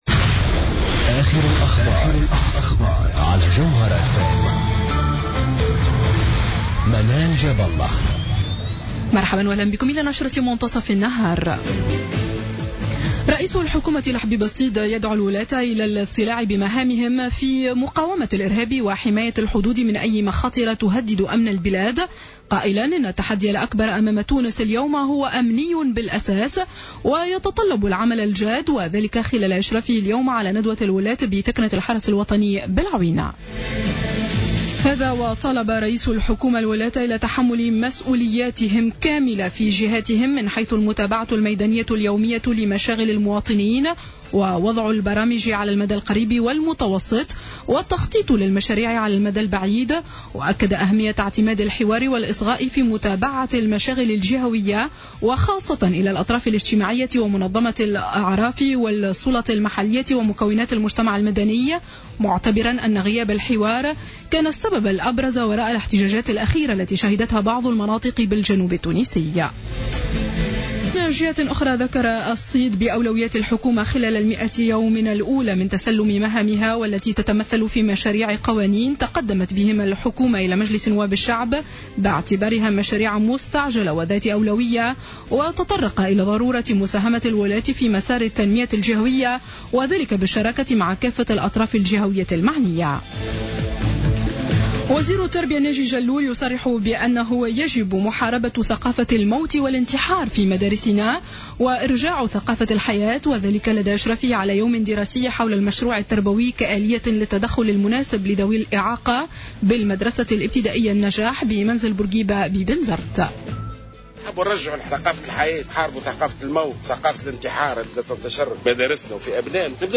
نشرة أخبار منتصف النهار ليوم السبت 21 فيفري 2015